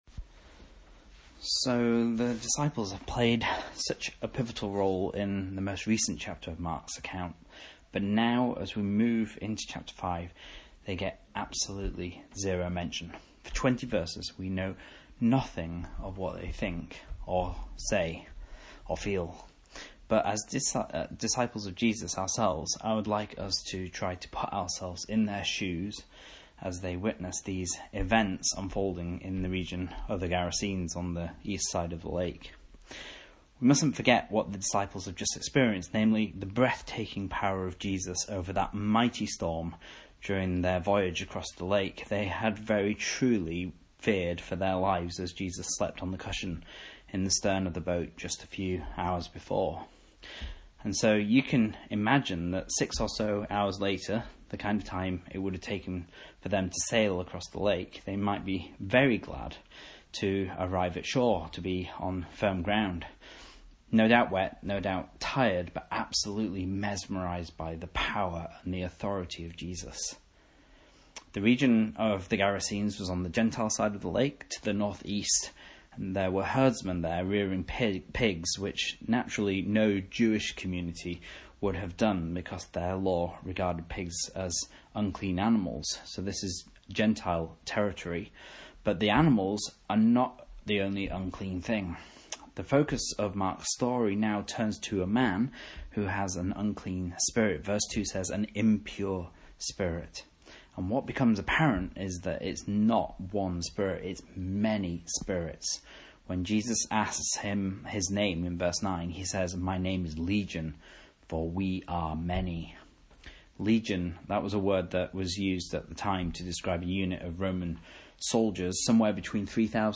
Message Scripture: Mark 5:1-20 | Listen